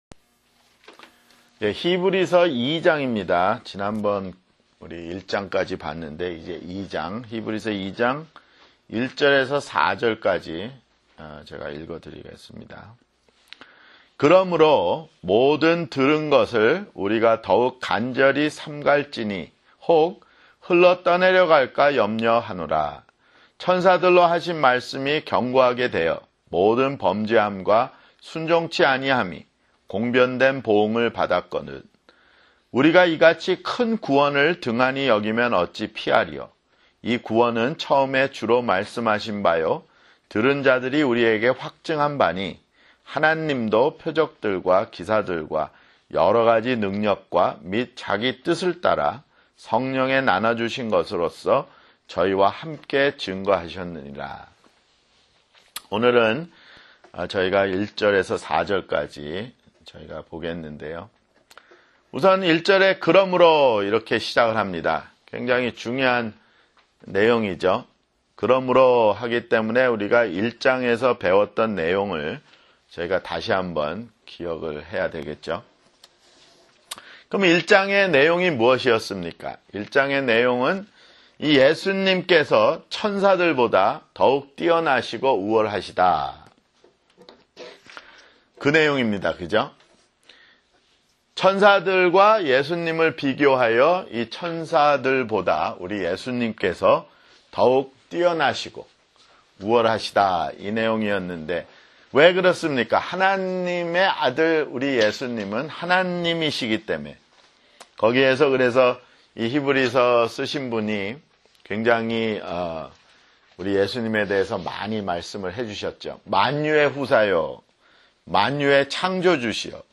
[성경공부] 히브리서 (7)